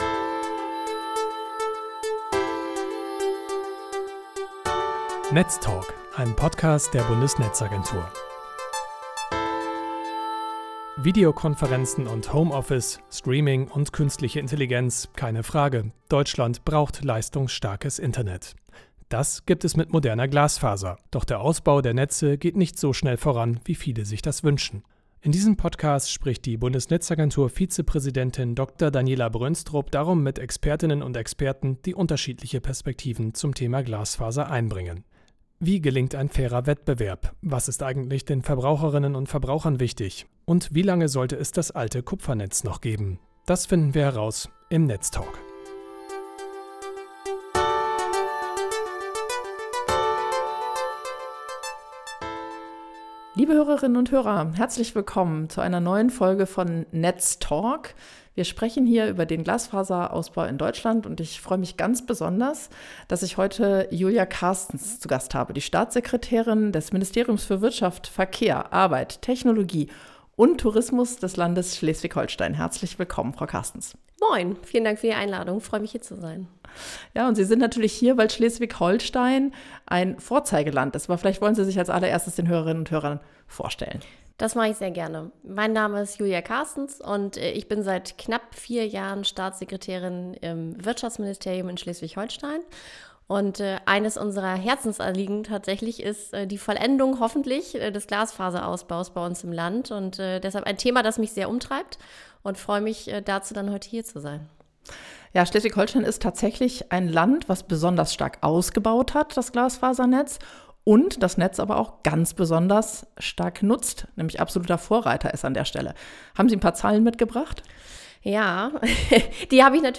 Wie immer moderiert BNetzA-Vizepräsidentin Dr. Daniela Brönstrup das Gespräch.